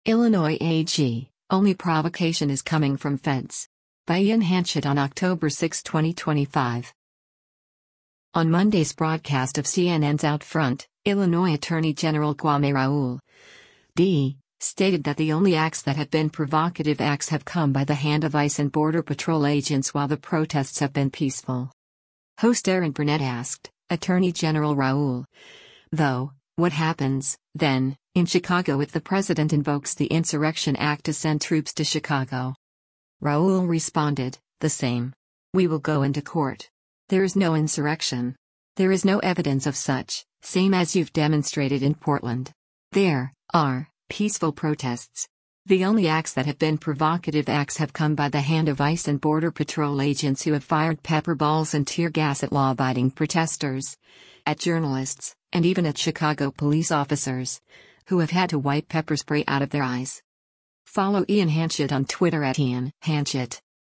On Monday’s broadcast of CNN’s “OutFront,” Illinois Attorney General Kwame Raoul (D) stated that “The only acts that have been provocative acts have come by the hand of ICE and Border Patrol agents” while the protests have been peaceful.
Host Erin Burnett asked, “Attorney General Raoul, though, what happens, then, in Chicago if the president invokes the Insurrection Act to send troops to Chicago?”